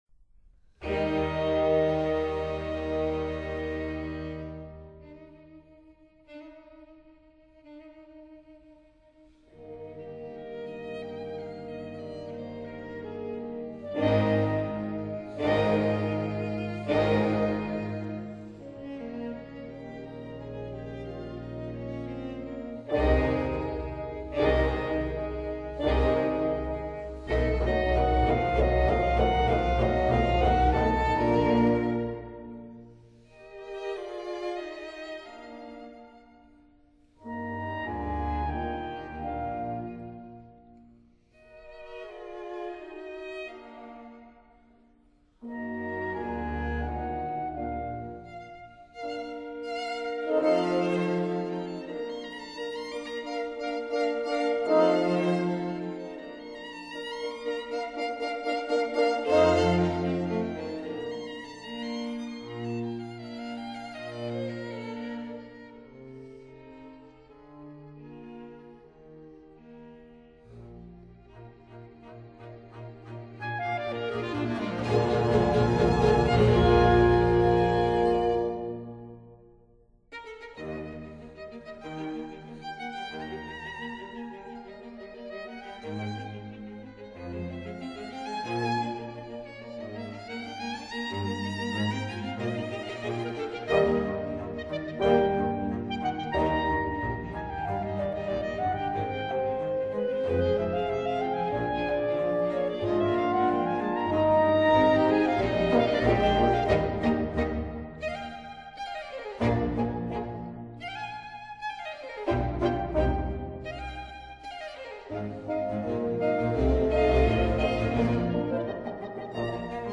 violin
cello
viola
double bass
clarinet
bassoon
horn